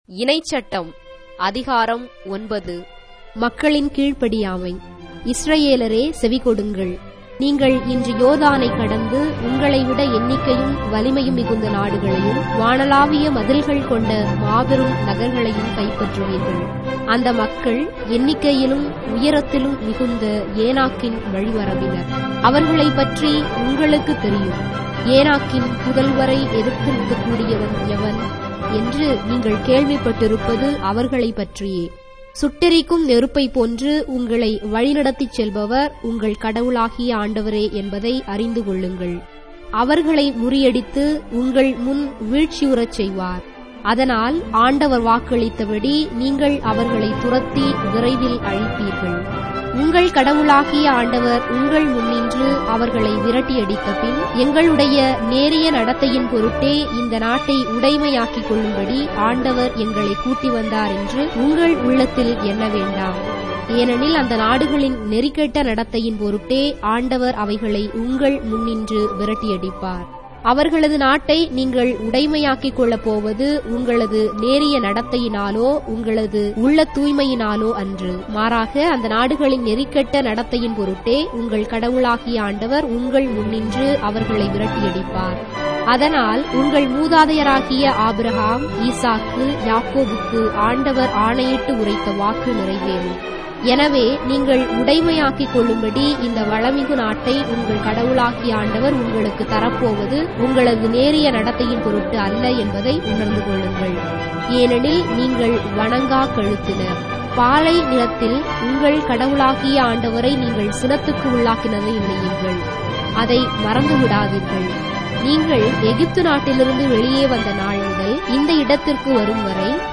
Tamil Audio Bible - Deuteronomy 7 in Ecta bible version